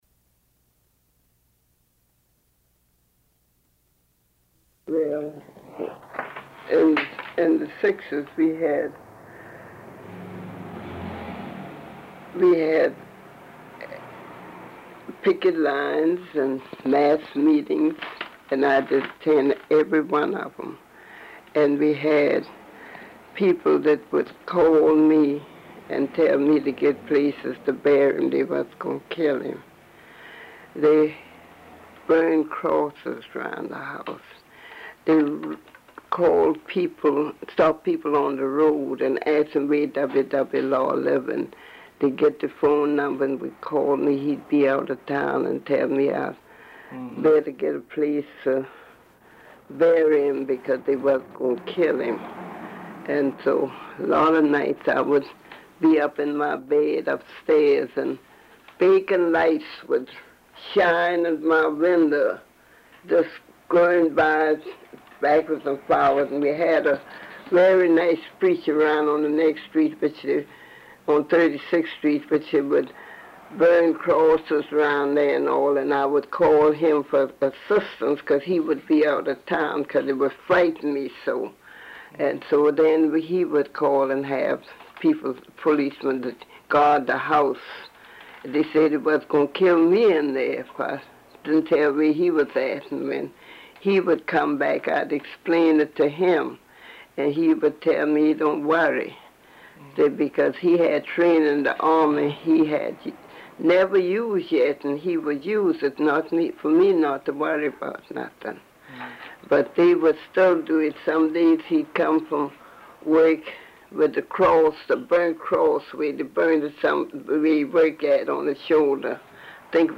Oral History
An interview